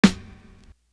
Englebert Snare.wav